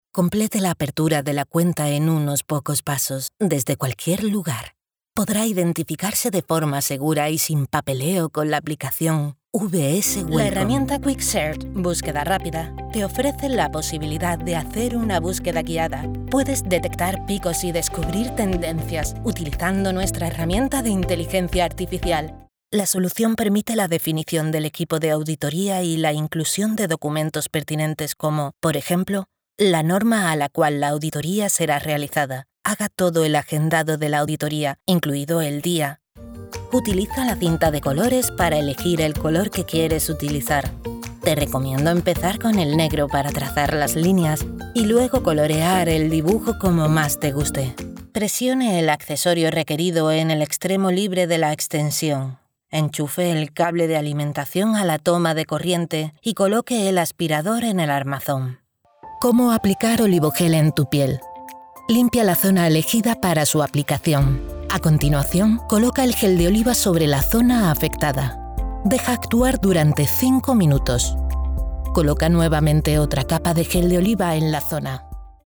Soy una locutora espaĂ±ola con acento espaĂ±ol castellano neutro. Voz femenina comercial, publicitaria, natural, convincente, elegante, juvenil, adulta.
Sprechprobe: eLearning (Muttersprache):
I have my own professional soundproof studio.